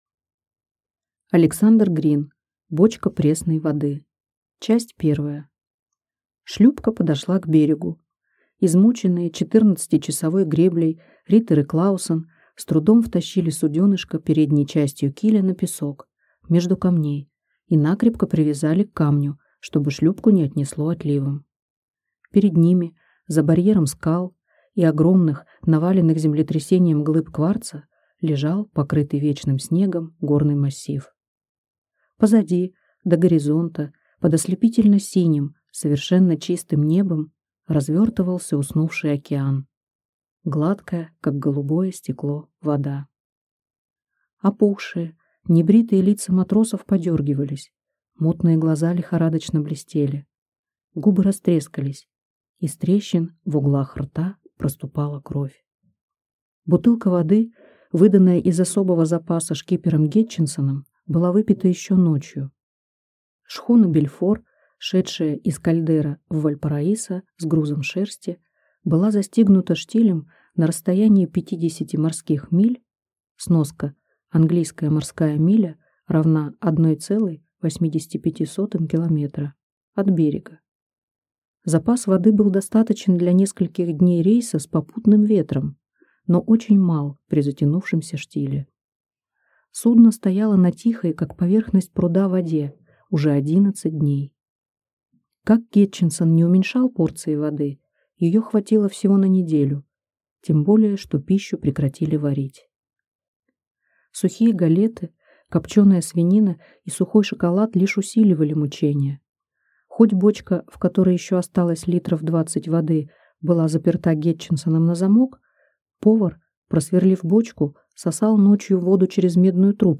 Аудиокнига Бочка пресной воды | Библиотека аудиокниг